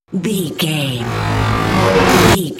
Dramatic riser flashback
Sound Effects
In-crescendo
Atonal
ominous
haunting
eerie